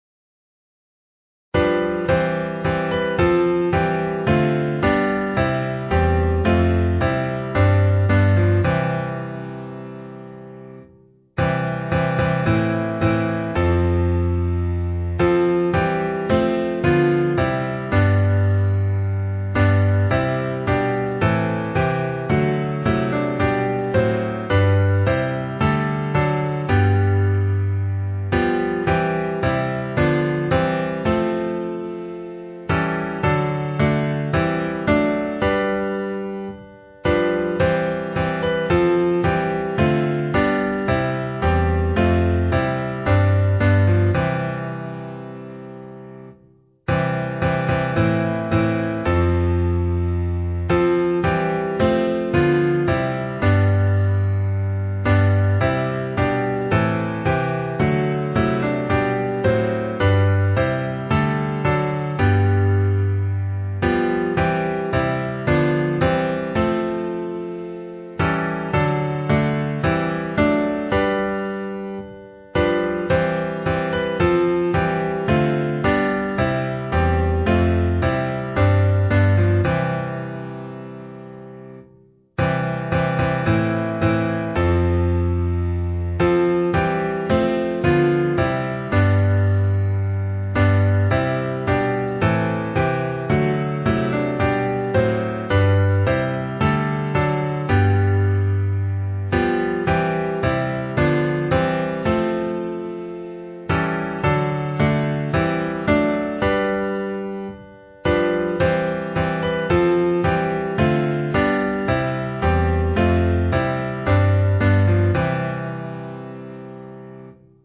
Key: E